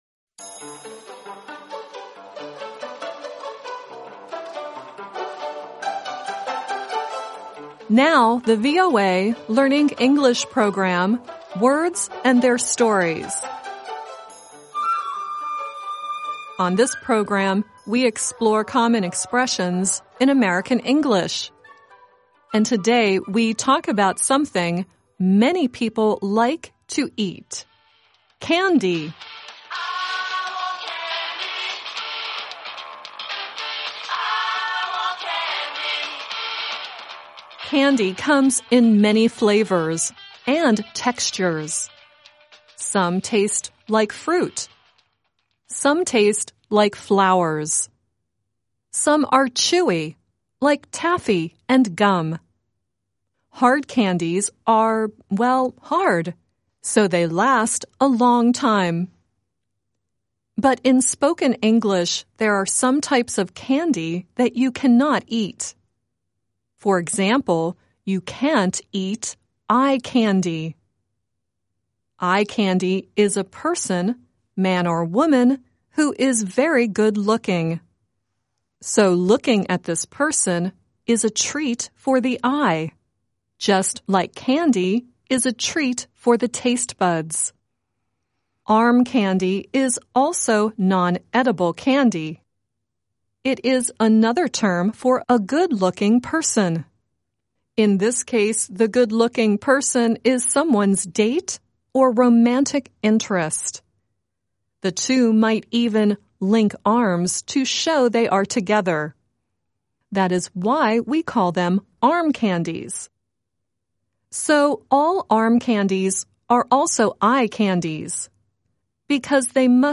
Earlier in the story, you heard the pop group Bow Wow Wow singing "I Want Candy." The song at the end is Sammy Davis Jr. singing "The Candy Man," which was first heard in the famous 1971 movie "Willie Wonka & the Chocolate Factory."